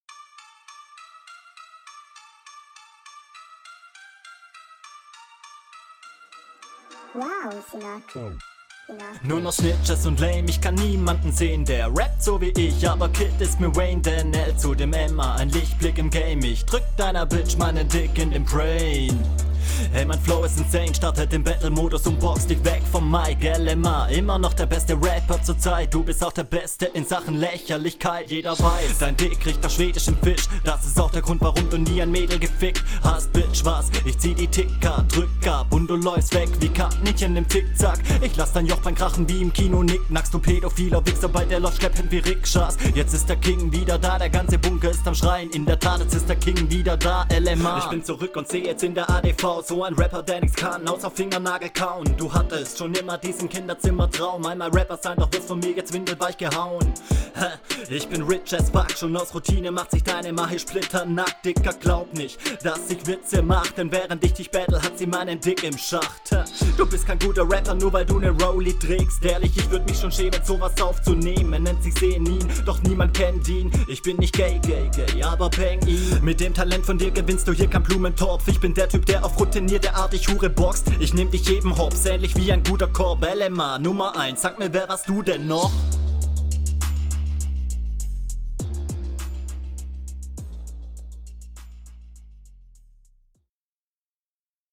Flow: cleaner flow, hier haste mal etwas schnellere Pattern. Macht das ganze etwas dynamischer.